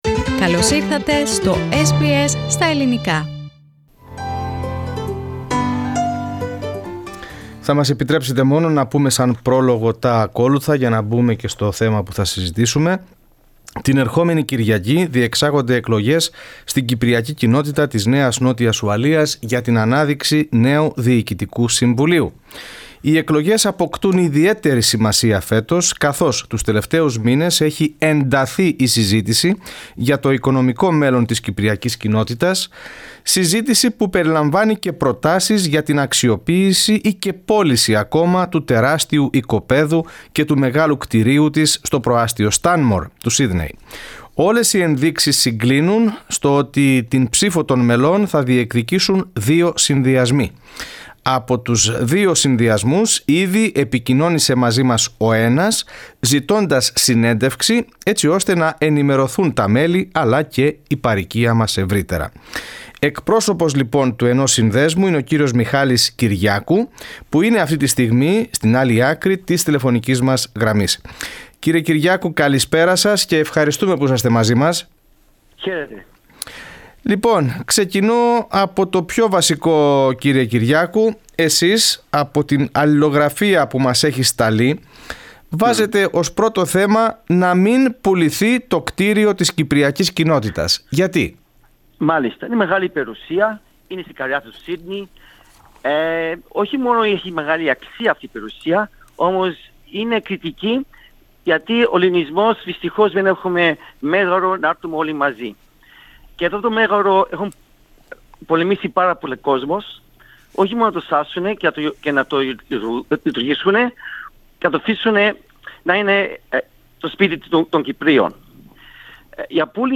Press Play to hear the full interview in Greek.